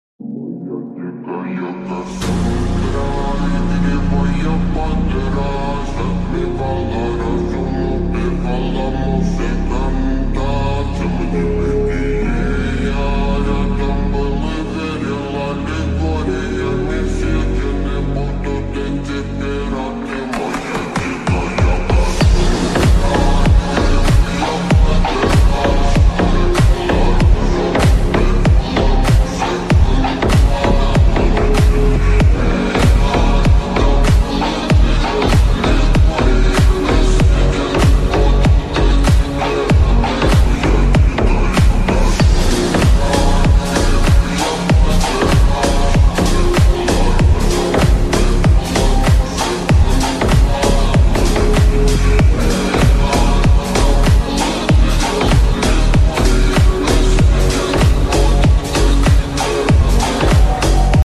BEST SUPER SLOWED MUSIC